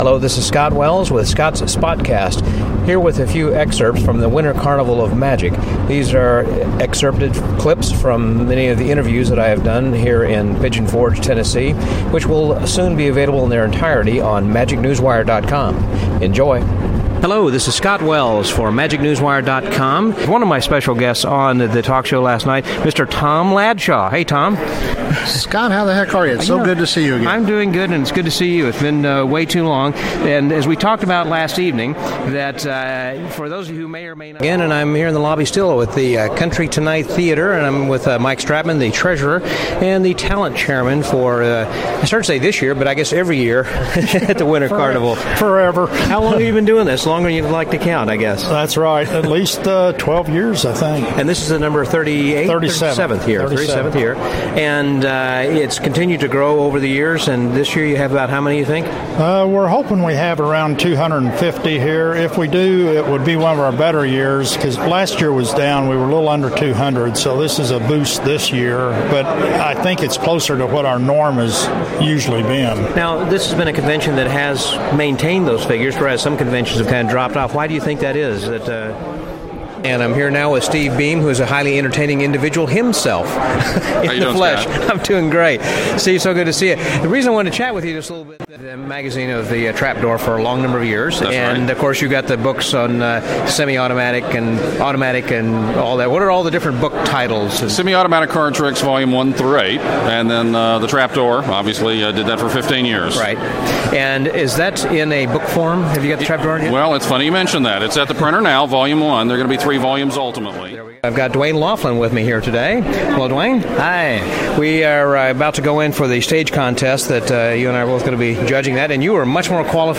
Clips from interviews at the Winter Carnival of Magic